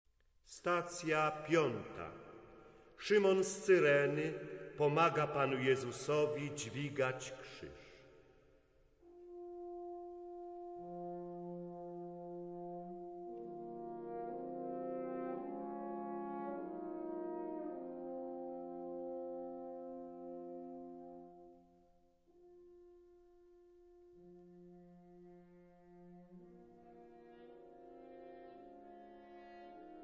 sopran/soprano
baryton/baritone